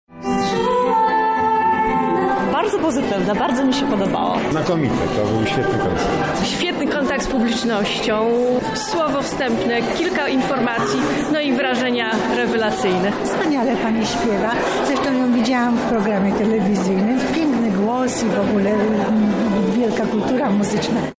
Portugalskie rytmy w Chatce Żaka.
„Niesamowita i utalentowana artystka” zgodnie mówią widzowie: